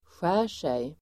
Uttal: [sj'ä:r_sej]